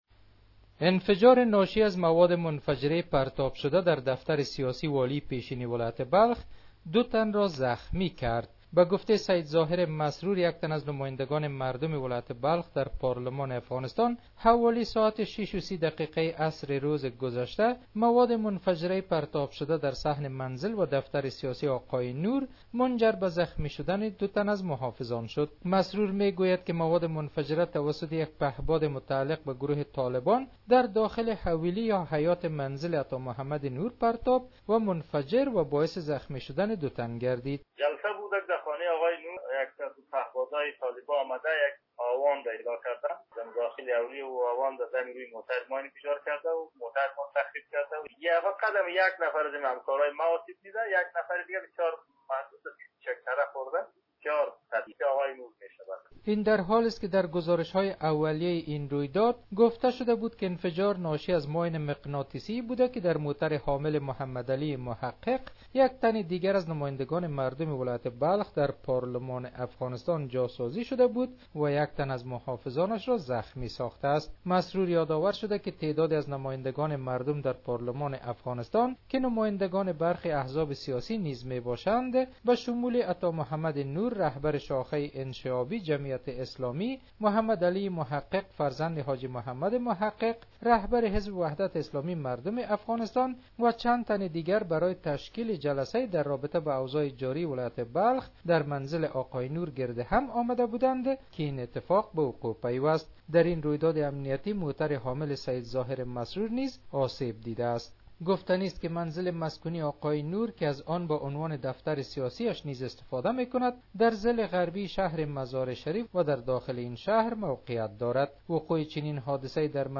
به گزارش خبرنگار رادیو دری ، در اثر برخورد این گلوله هاوان دوتن از محافظان عطا محمد نور و سید ظاهر مسرور از نمایندگان مردم بلخ زخمی شدند.